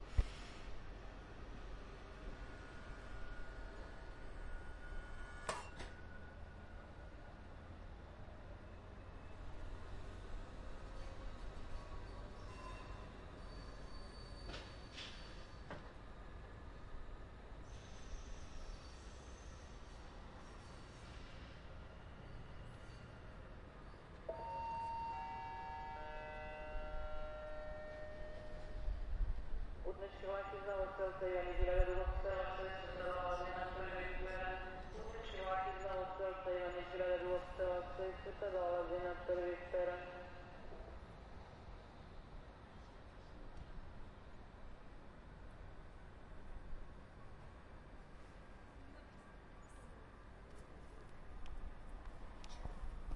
达拉斯土伦声景 " 452011 联合车站安静的轨道旁
描述：达拉斯/土伦声景交流项目的一部分日期：452011地点：达拉斯联合车站附近时间密度达拉斯，联合车站靠近铁轨时间密度。3复音密度。3忙碌：2混乱：2
标签： 脚步 安静 火车 火车喇叭 列车站 哨子
声道立体声